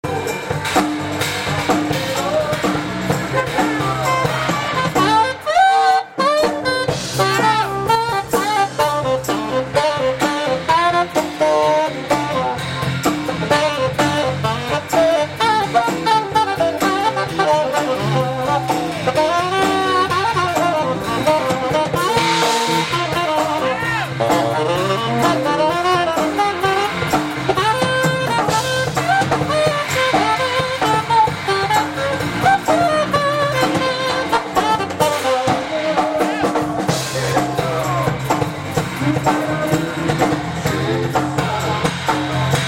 *Tthese are audio clips from semi-recent gigs. The quality speaks for itself, however the ensemble playing is worth a listen. Most of the clips are from a gig on the Battleship New Jersey on the Delaware River the summer of 2002. It was a great setting to play some jazz while people checked out the surroundings.
Bass
Keys
Sax